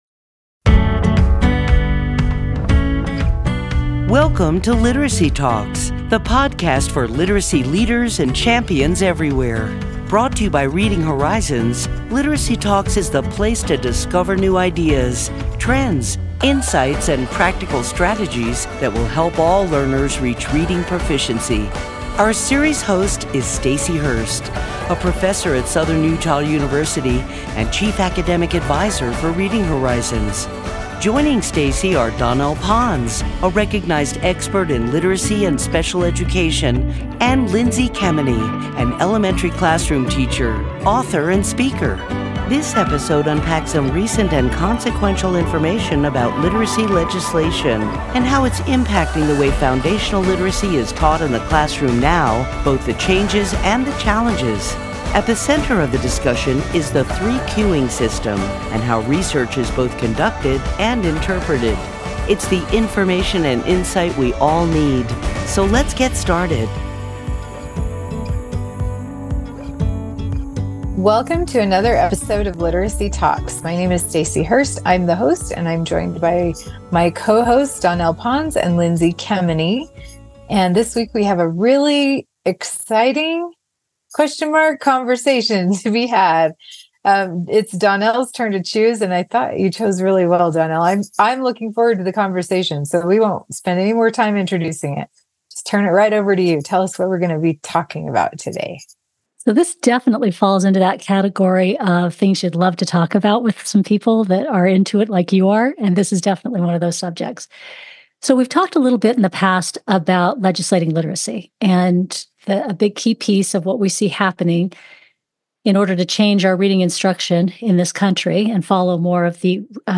In October of 2023, The Reading Council of North America filed a lawsuit against Ohio, attempting to reverse the state’s move to align classroom instruction to the science of reading principles and practices. In this episode of Literacy Talks, our hosts discuss the growing momentum behind instruction based in the science of reading and the importance of looking closely, carefully, and analytically at the research that supports any approach. Listeners will hear common sense, clear thinking, and data-informed perspectives about choosing curricula and staying involved in legislative initiatives.